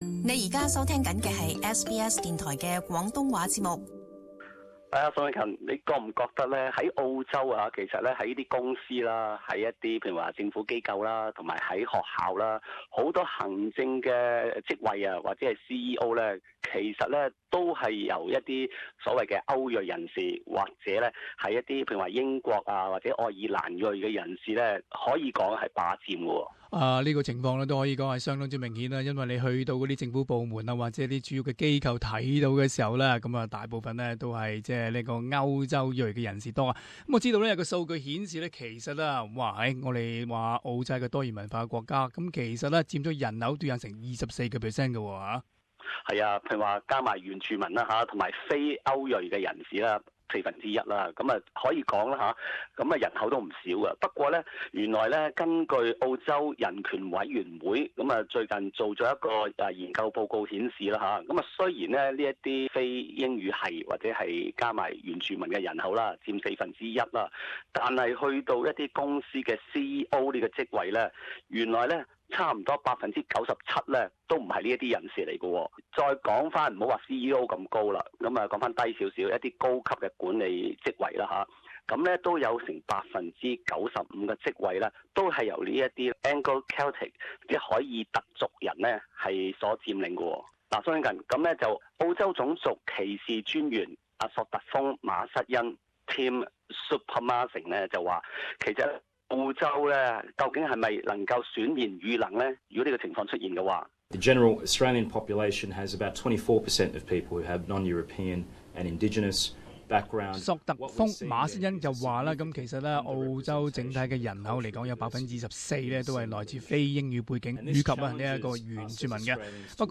【時事報導】澳洲企業領袖維持單一族裔壟斷局面